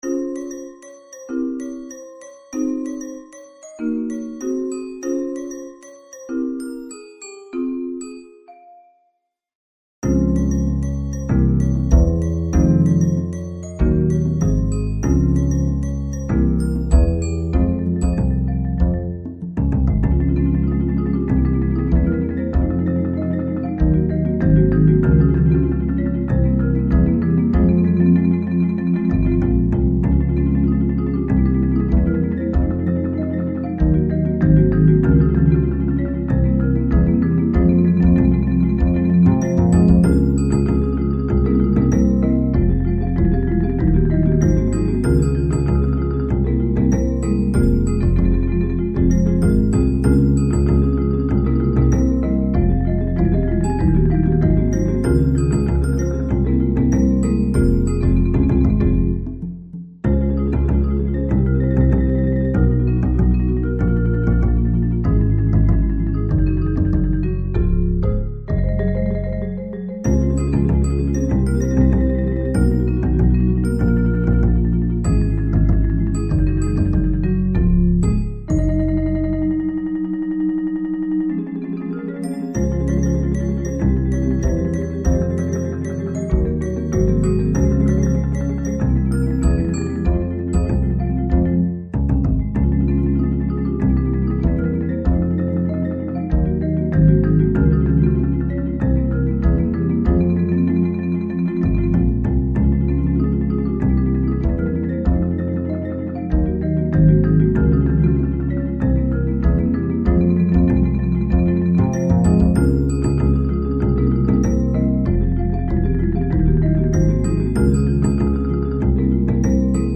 Mallet-Steelband Muziek
Bells Xylofoon Marimba Bass Percussion Drums